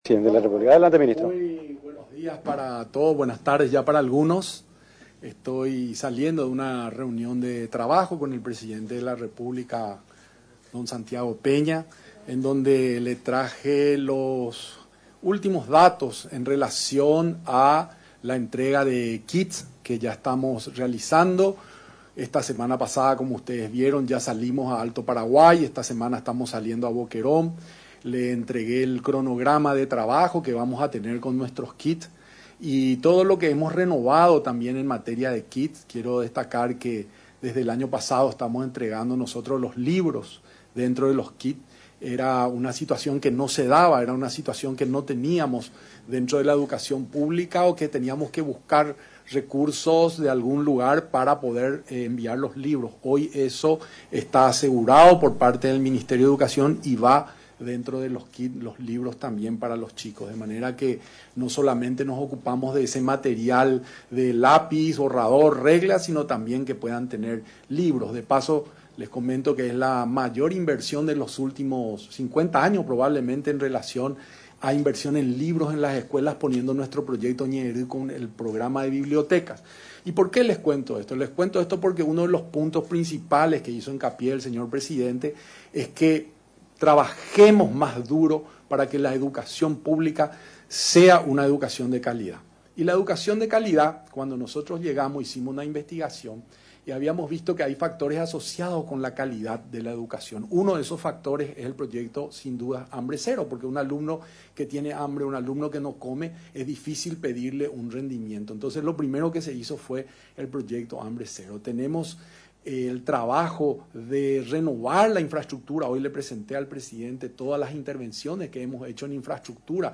En rueda de prensa, realizada en la Residencia Presidencial Mburuvichá Róga, resaltó que el mandatario paraguayo, hizo hincapié en trabajar más duro en una educación de calidad.